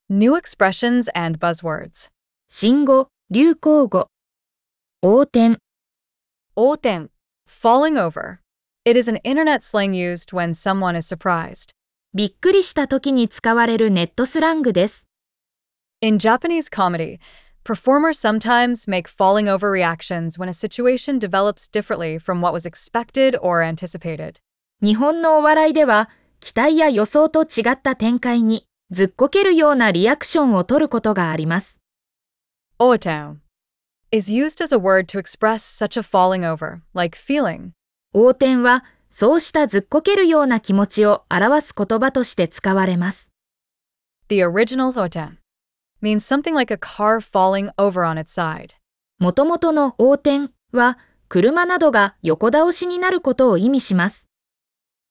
🗣 pronounced: Outen